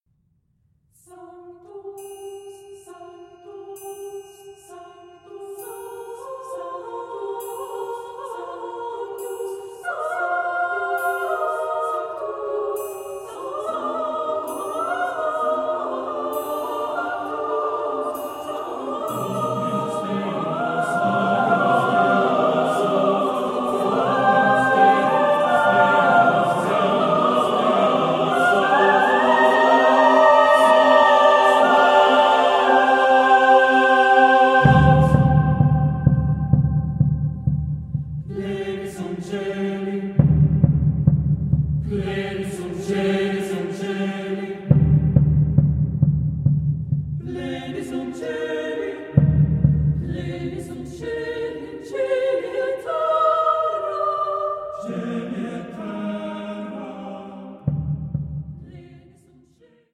Genre-Style-Form: Sacred ; Mass ; Double choir
Mood of the piece: andante cantabile
Type of Choir: SATB + SATB  (8 mixed voices )
Instrumentation: Percussion  (2 instrumental part(s))
Instruments: Triangle (1) ; Drum (1)
Tonality: free tonality